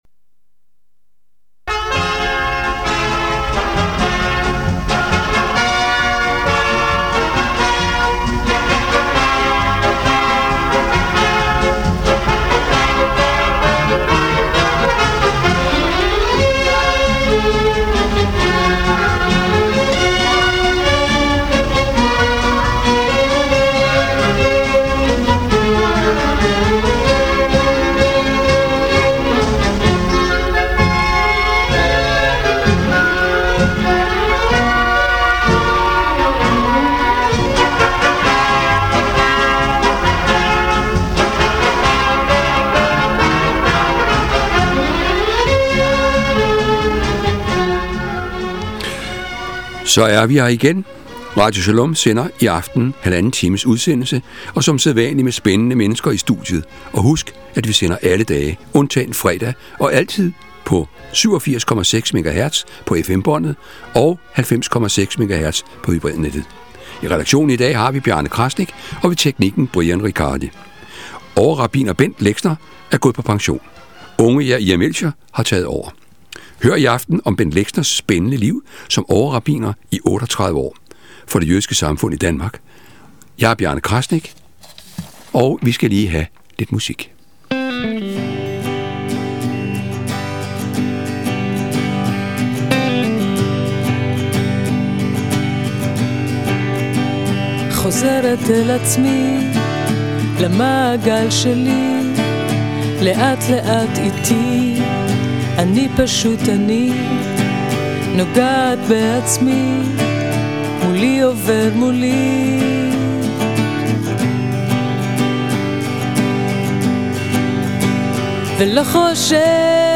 Beskrivelse: Interview